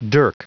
Prononciation du mot dirk en anglais (fichier audio)
Prononciation du mot : dirk